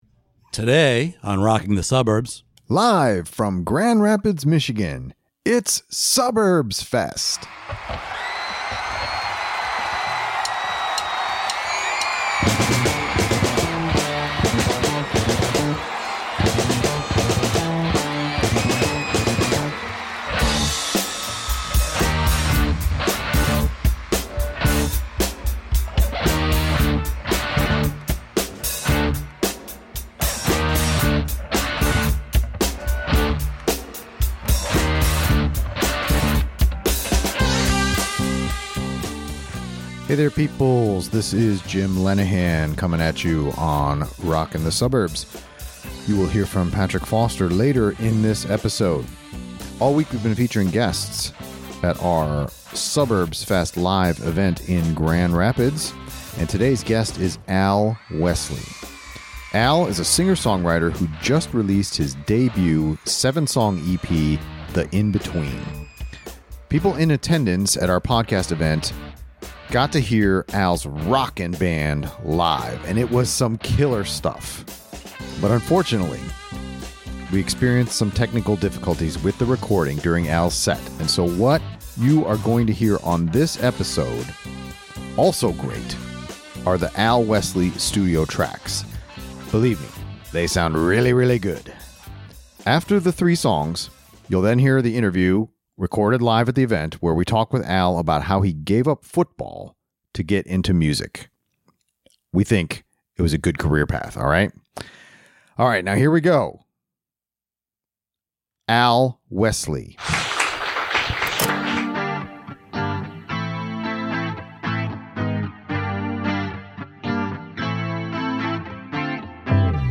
We are coming at you live from Suburbs Fest in Grand Rapids MI.